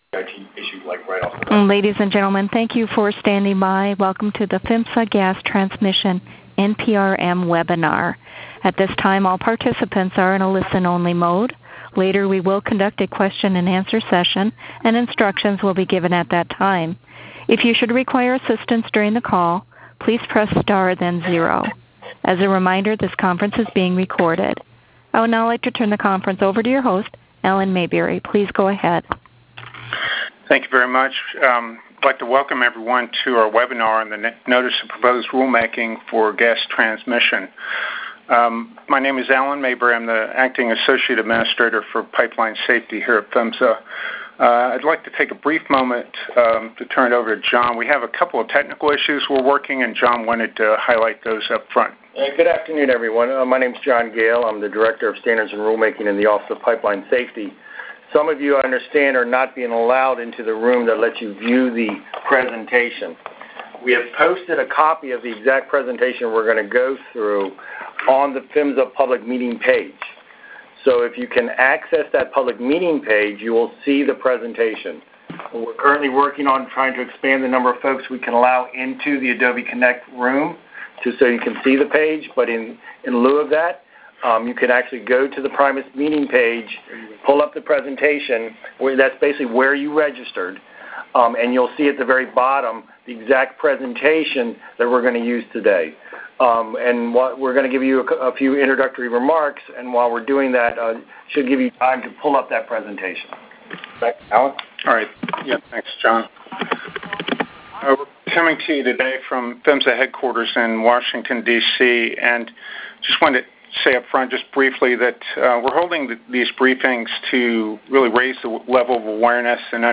Overview: This public webinar is being hosted by the U.S. Department of Transportation's Pipeline and Hazardous Materials Safety Administration (PHMSA) to discuss the proposed rulemaking for the safety of the nation's gas transmission pipelines.
At the sessions, PHMSA senior staff will present factual information about the regulatory proposal and will answer clarifying questions intended to help you comment more knowledgeably. Among a number of topics in the rulemaking proposal, PHMSA is proposing to update integrity management (IM) requirements and to address issues related to non-IM requirements for natural gas transmission and gathering pipelines.